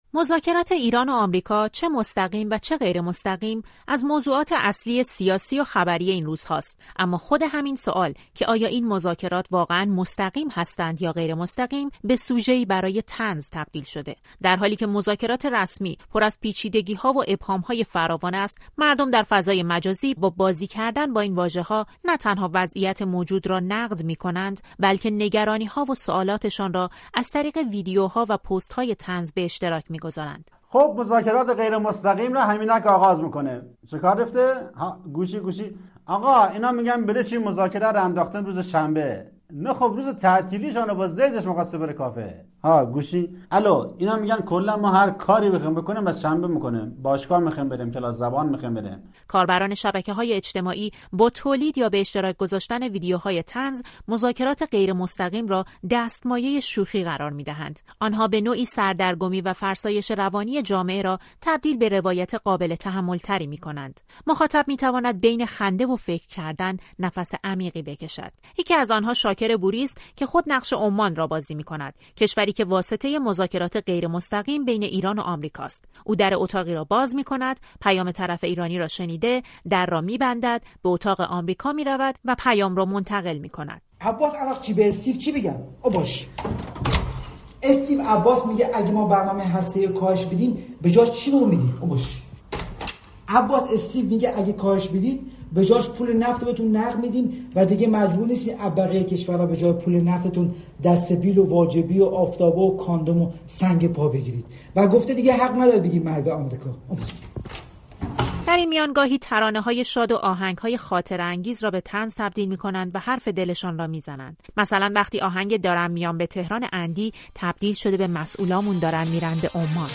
همزمان با داغ شدن بحث‌ها پیرامون مذاکرات ایران و آمریکا، شبکه‌های اجتماعی پر شده از واکنش‌های طنزآمیز مردم؛ شوخی‌هایی که در عین خنده‌دار بودن، ابزاری برای نقد سیاسی و بازتاب نگرانی‌ها و روایت غیررسمی جامعه از موضوعات پیچیده‌ی سیاسی‌ ‌است. در این گزارش رادیویی به این واکنش‌های طنزآلود پرداخته‌ایم.